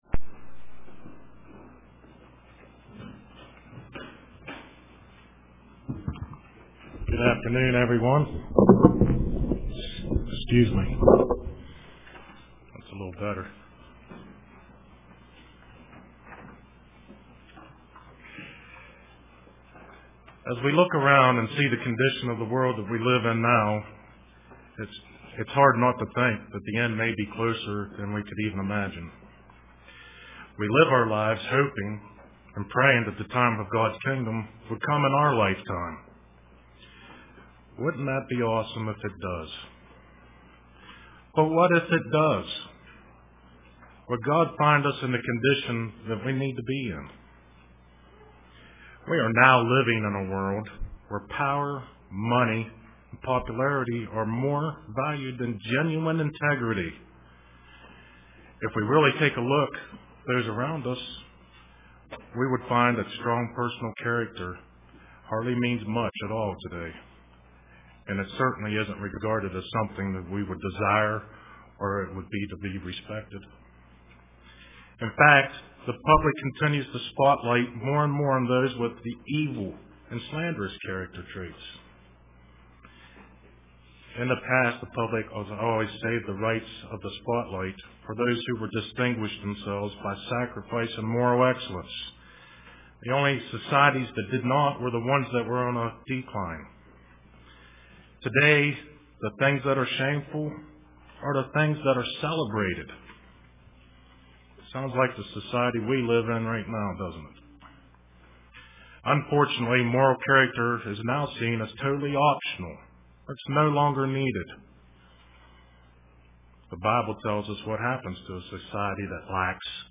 Print An Obedient Heart UCG Sermon Studying the bible?